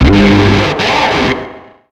Cri de Blizzaroi dans Pokémon X et Y.